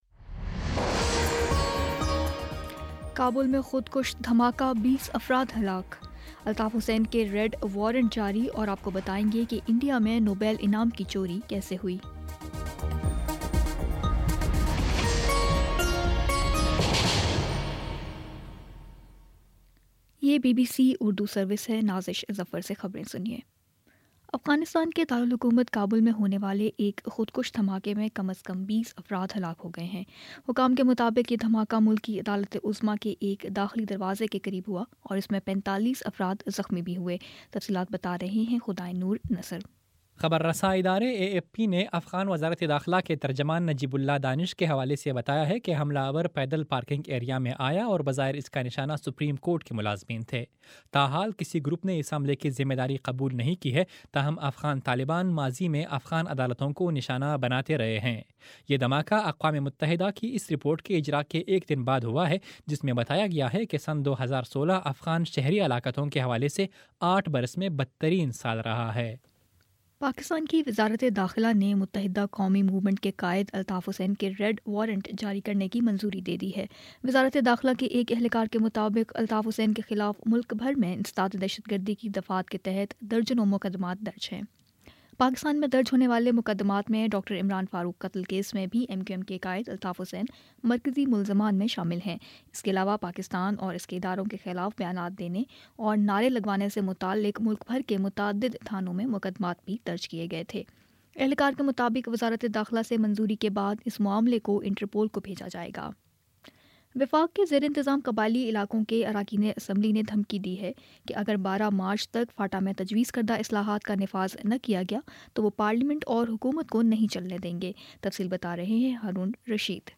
فروری 07 : شام سات بجے کا نیوز بُلیٹن